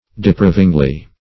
depravingly - definition of depravingly - synonyms, pronunciation, spelling from Free Dictionary Search Result for " depravingly" : The Collaborative International Dictionary of English v.0.48: Depravingly \De*prav"ing*ly\, adv.
depravingly.mp3